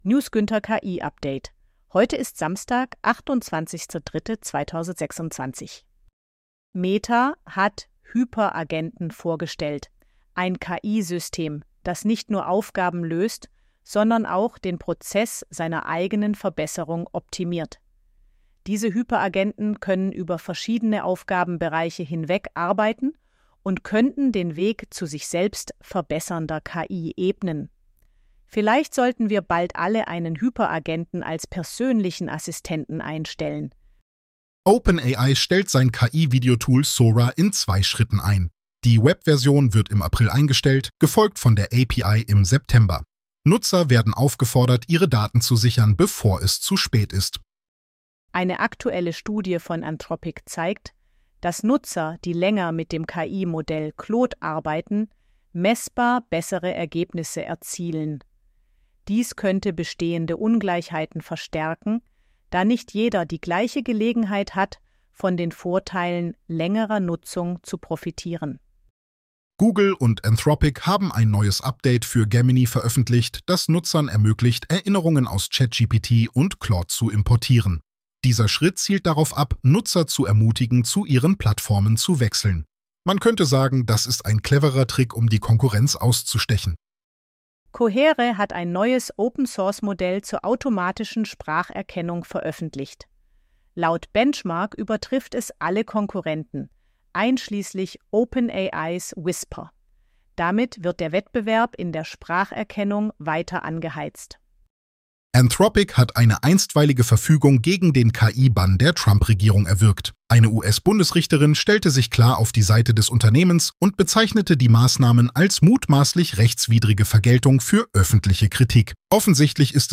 Täglich neu – die wichtigsten Meldungen aus der Welt der Künstlichen Intelligenz, übersetzt und aufgesprochen.